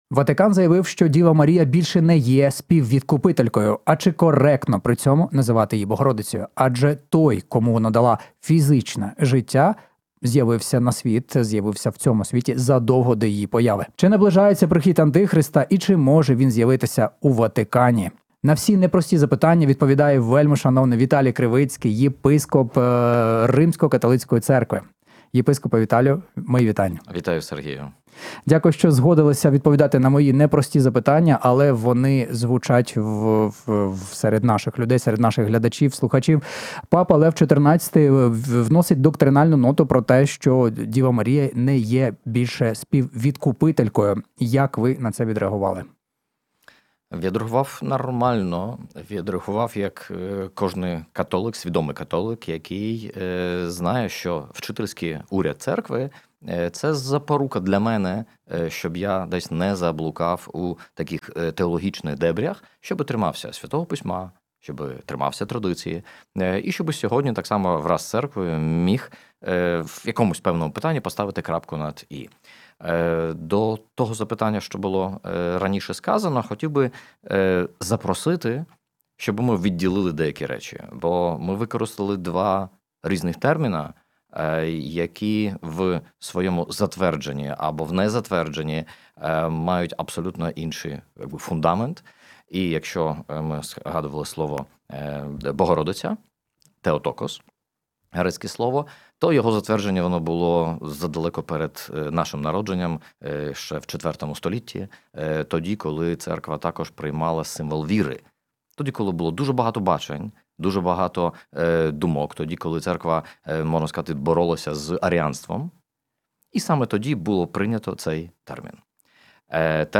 Чи коректно називати її Богородицею, якщо Той, Кому вона дала фізичне життя, існував задовго до її народження? Чи наближається прихід антихриста і чи може він з’явитися у Ватикані? На ці непрості запитання відповідає вельмишановний Віталій Кривицький — єпископ Римсько-Католицької Церкви.
Ефір програми Біблійний погляд Більше не Співвідкупителька — а чи досі Богородиця?